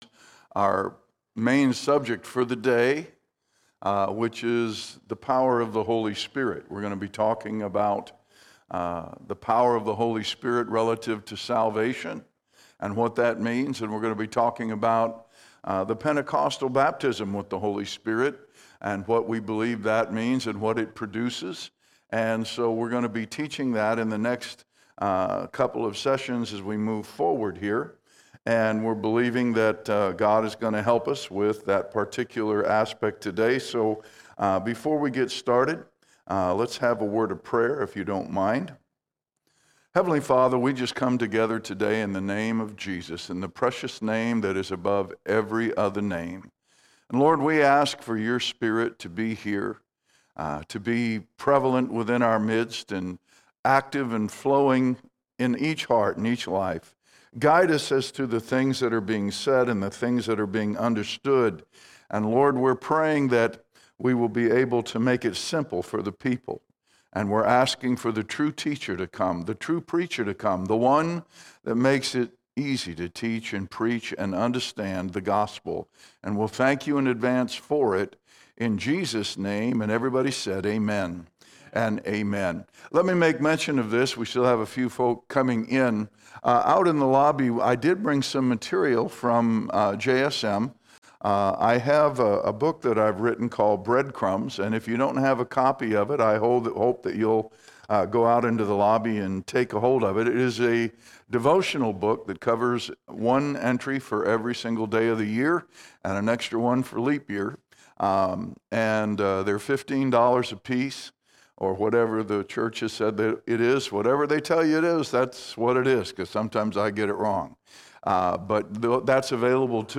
29 April 2023 Topic: Holy Spirit All Sermons Lifestyle Witness Lifestyle Witness The Holy Spirit helps us live a lifestyle as a witness of Jesus Christ.